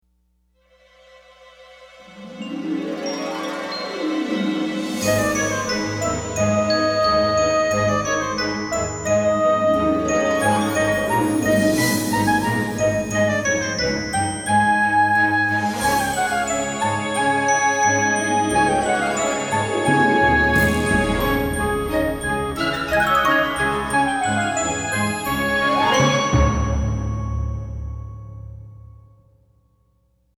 Thematic
Orchestral